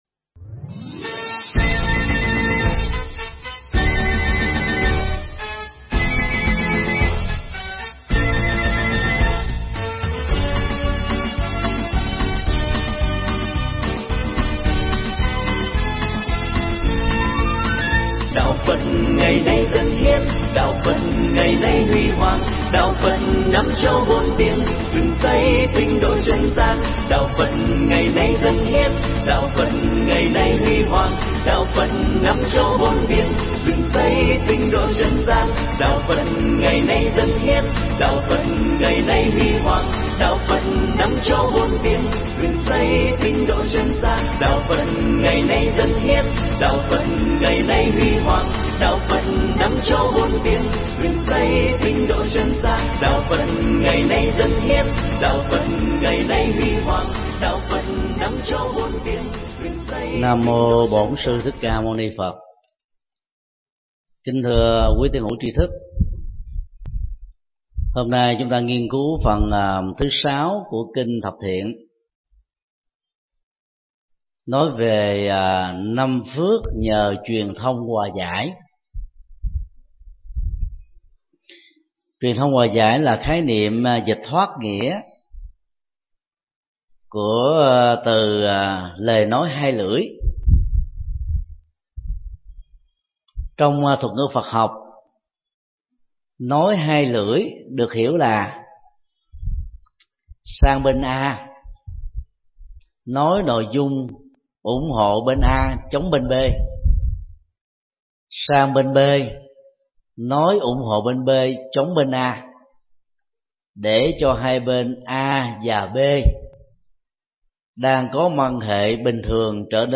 Tải mp3 bài giảng Kinh Thập Thiện 06: 5 phước nhờ truyền thông hòa giải do thầy Thích Nhật Từ giảng tại chùa Giác Ngộ, ngày 20 tháng 05 năm 2012.